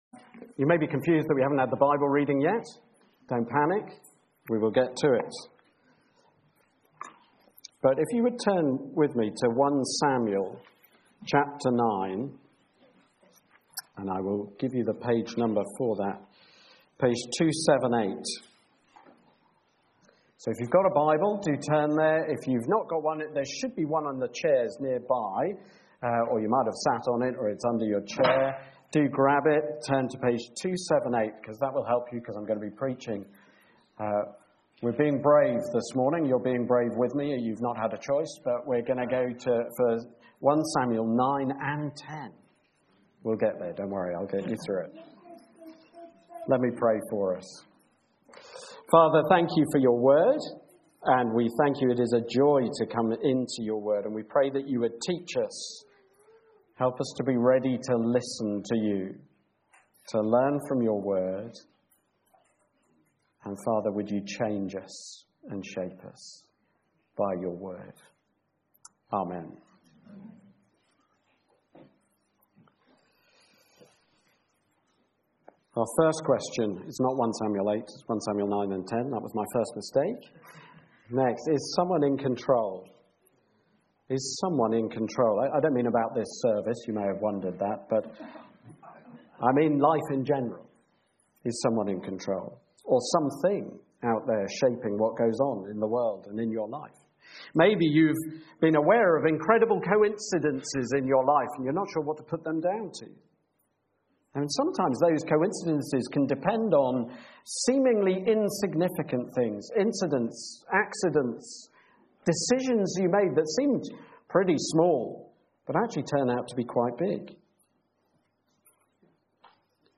Sermon (Part 1) Bible Reading Sermon (Part 2)